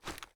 gear_rattle_weap_medium_01.ogg